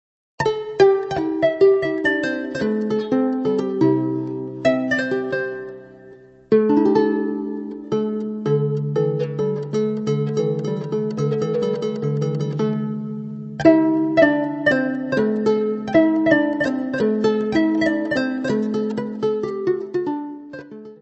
: stereo; 12 cm
Área:  Tradições Nacionais